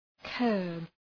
Προφορά
{kɜ:rb}